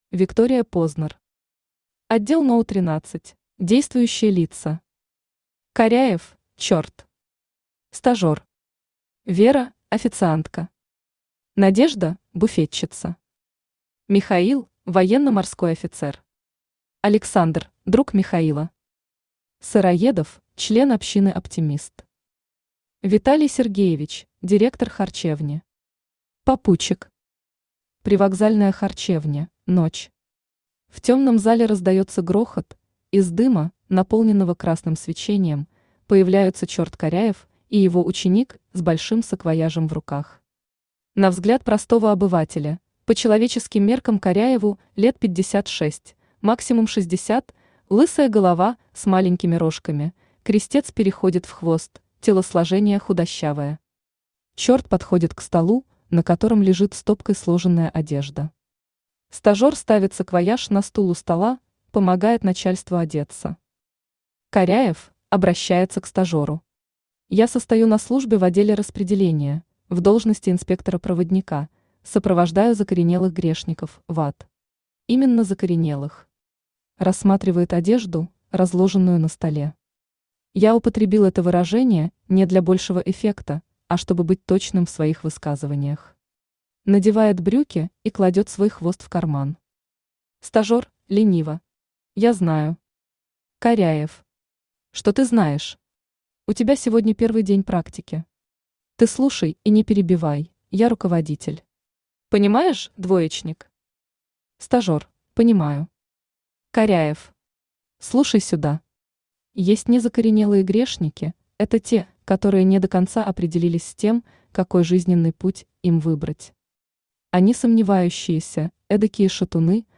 Аудиокнига Отдел № 13 | Библиотека аудиокниг
Aудиокнига Отдел № 13 Автор Виктория Познер Читает аудиокнигу Авточтец ЛитРес.